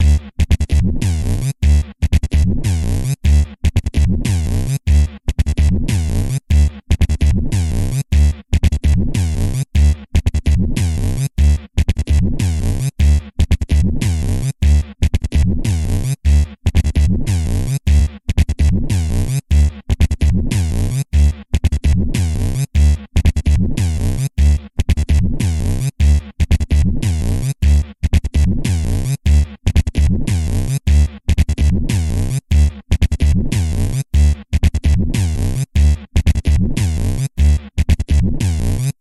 重低音が響くサウンド。ドゥ、ドゥドゥドゥドゥと繰り返す。ハウスミュージックのサブジャンルでもあるベースハウスサウンド。